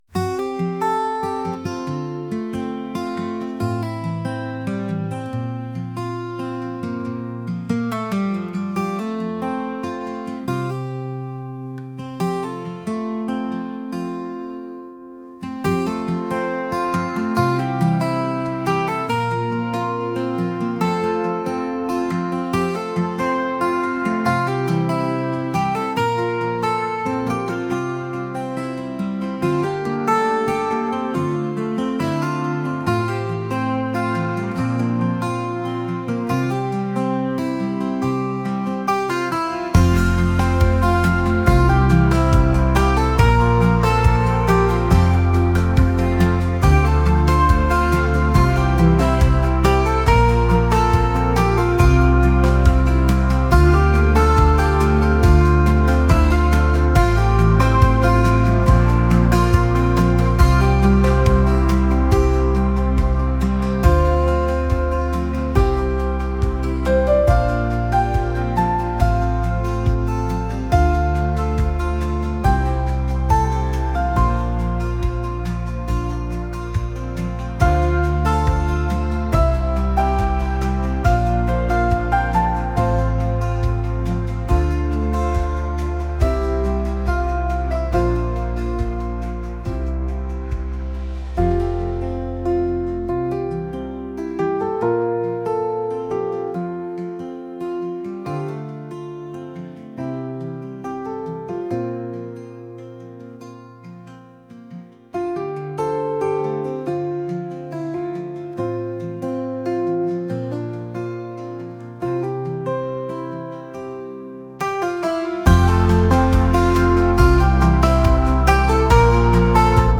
acoustic | folk | indie